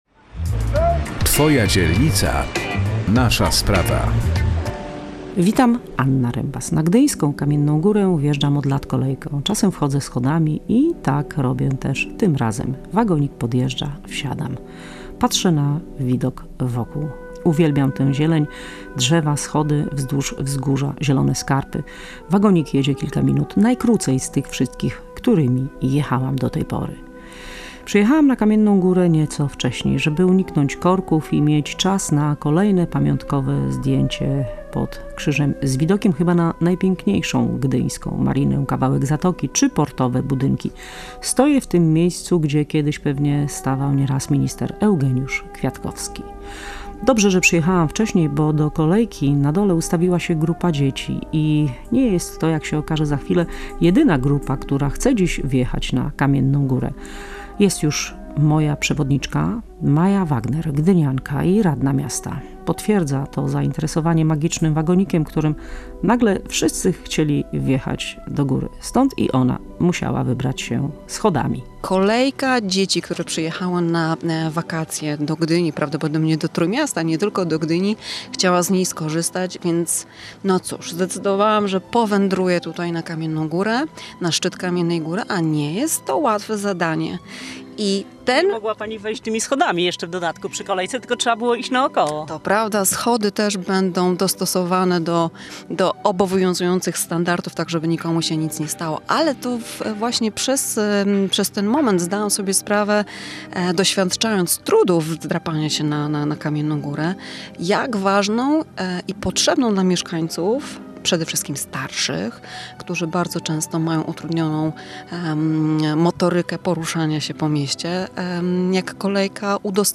Zapraszamy na spacer po Kamiennej Górze